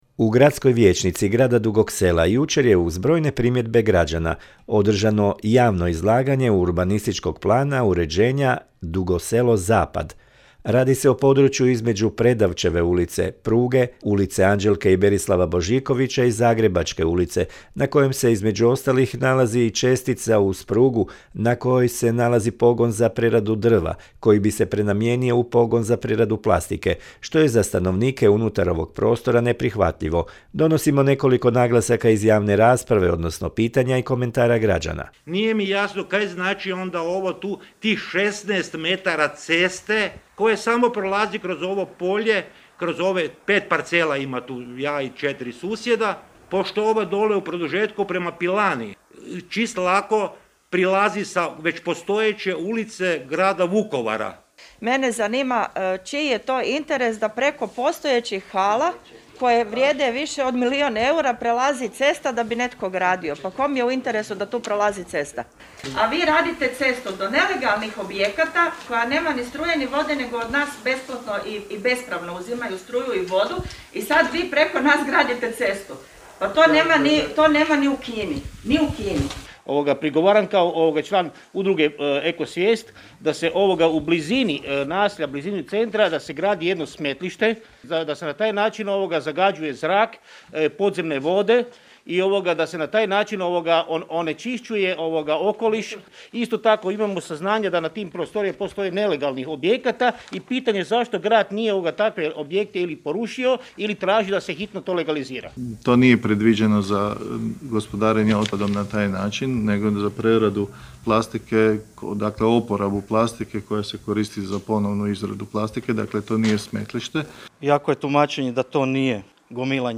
U Gradskoj vijećnici Dugog Sela jučer je, uz brojne primjedbe građana, održano javno izlaganje Urbanističkog plana uređenja Dugo Selo -Zapad.
Donosimo nekoliko naglasaka iz javne rasprave odnosno pitanja i komentara građana.